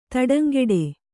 ♪ taḍaŋgṛḍe